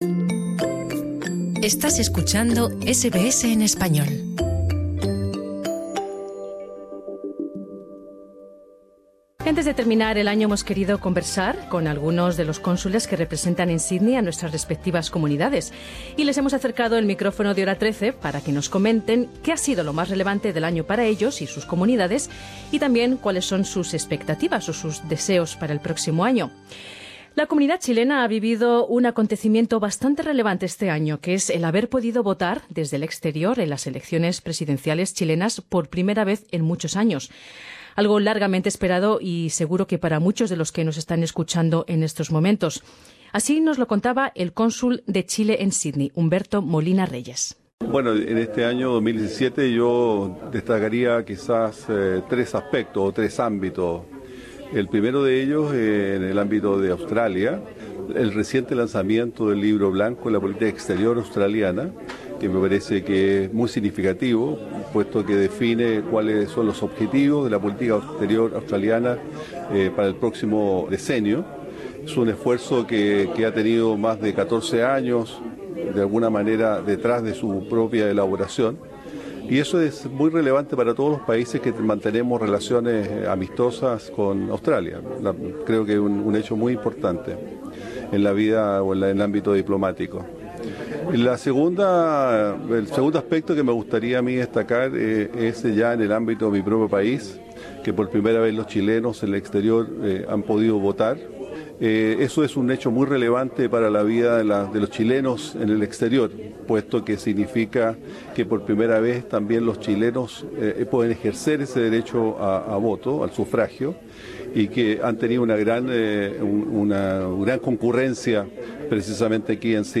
Representantes de nuestras comunidades nos cuentan qué fue lo más importante en el año que termina y qué esperan para el 2018. Escucha aquí a los cónsules de Peru, Chile y España.